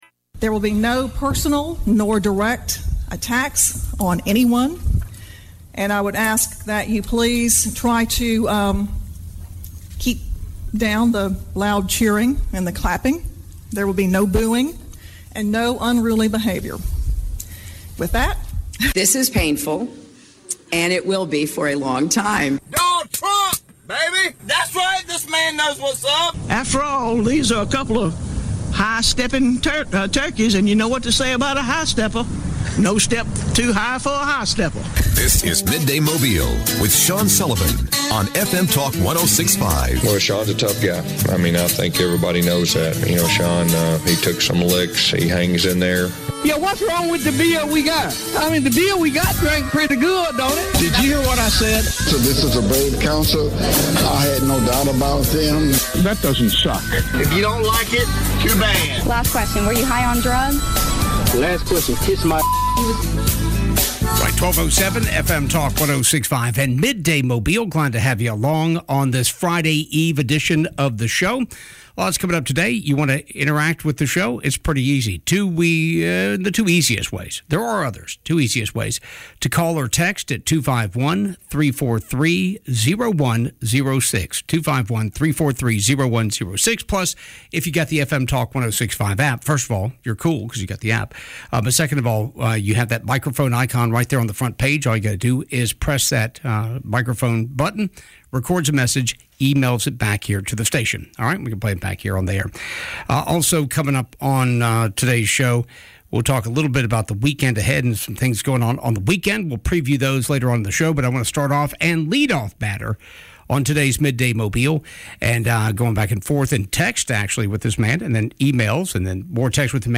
Veteran Special Interview